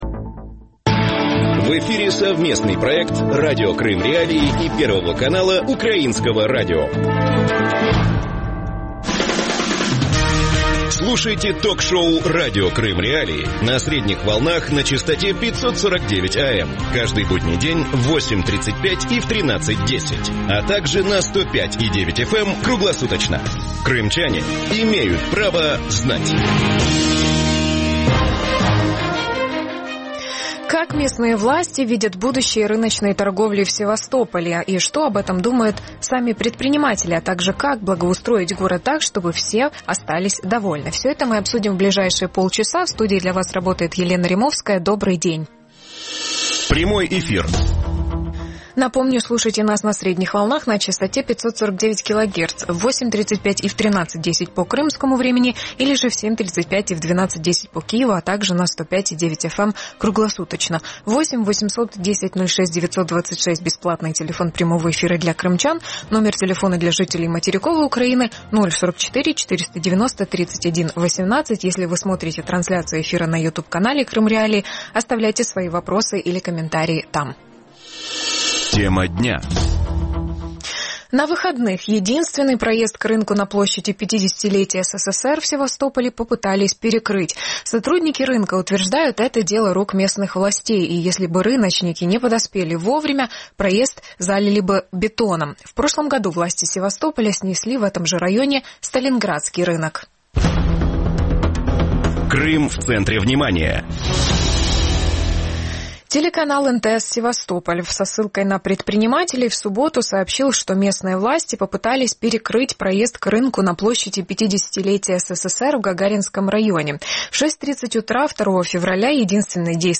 Из-за чего возник конфликт российских чиновников Севастополя и торговцев? Как местная администрация видит будущее рыночной торговли в Севастополе? И что собираются делать предприниматели, если их лишат бизнеса? Гости эфира